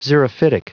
Prononciation du mot xerophytic en anglais (fichier audio)
Prononciation du mot : xerophytic